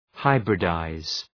Προφορά
{‘haıbrı,daız}